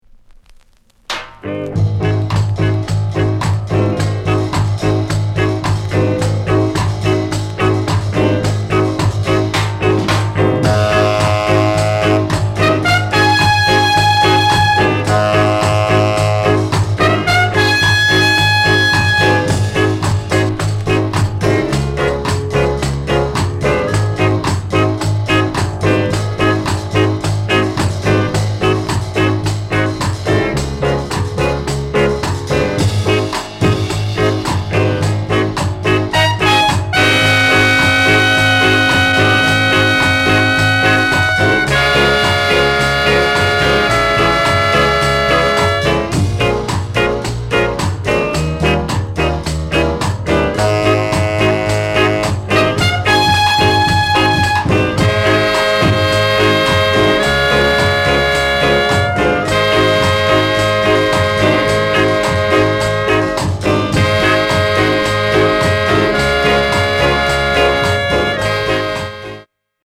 NICE SKA INST